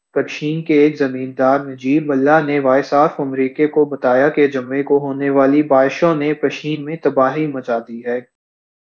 Spoofed_TTS/Speaker_13/263.wav · CSALT/deepfake_detection_dataset_urdu at main